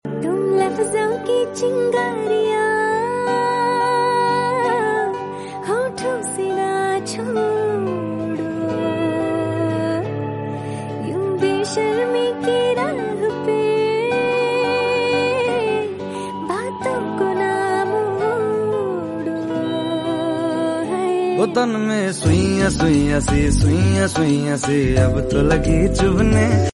Bollywood Music